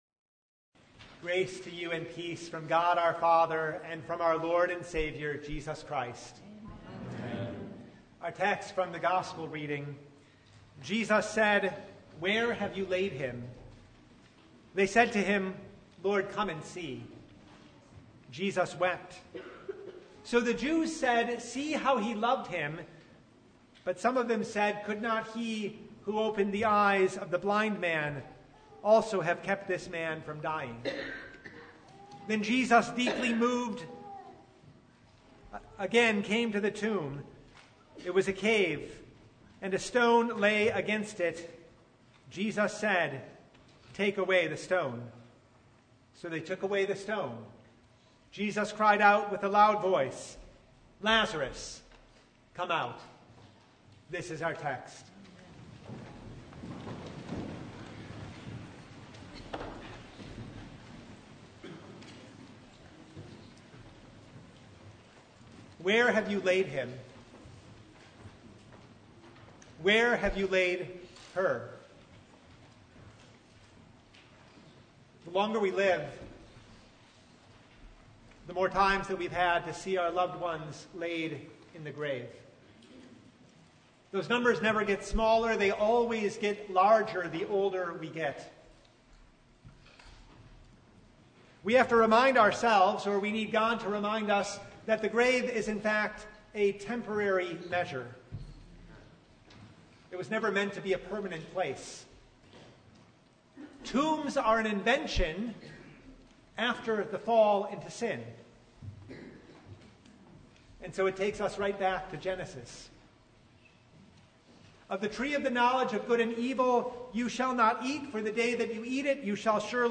John 11:1–53 Service Type: Sunday From the time of Adam
Truly, He is the resurrection and the life. Topics: Sermon Only « Oh, the Humility!